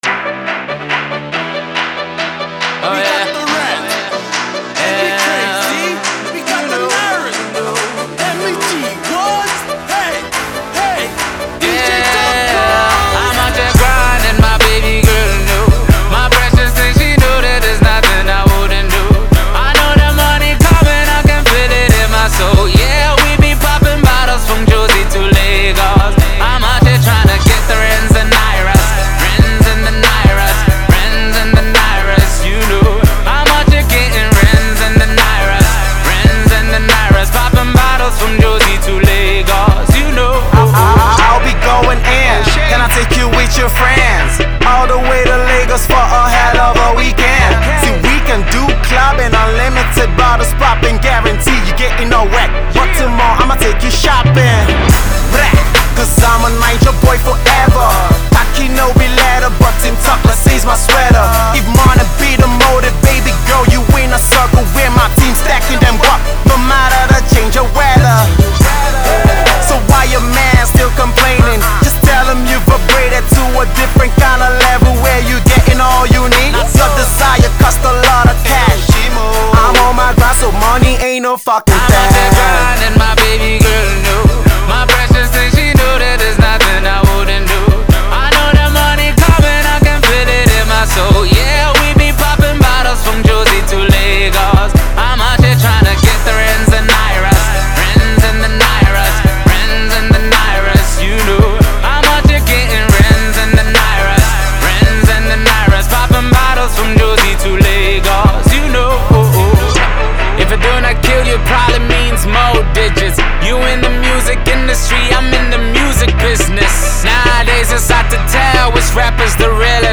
Hefty banger!
Hip-Hop club banger